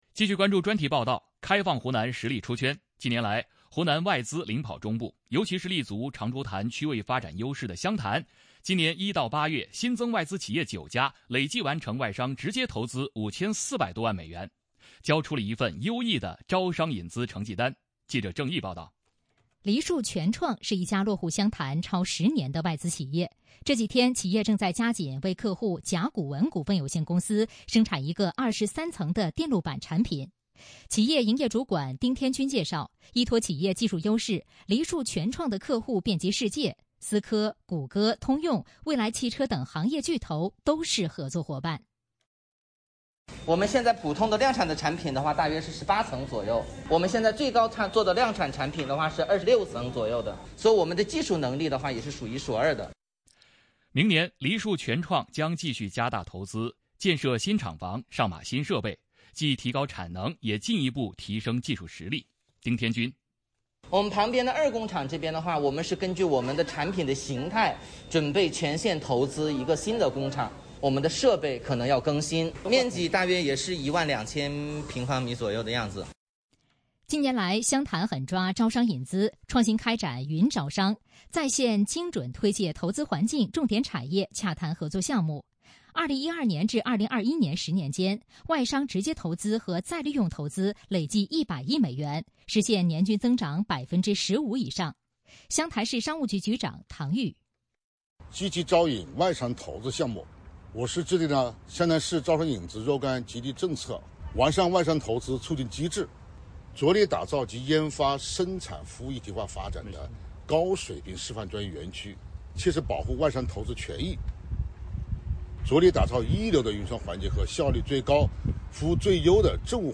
继续关注专题报道《开放湖南 实力“出圈”》。近年来，湖南外资领跑中部，尤其是立足“长株潭”区位发展优势的湘潭，今年1到8月新增外资企业9家，累计完成外商直接投资5400多万美元，交出了一份优异的招商引资成绩单。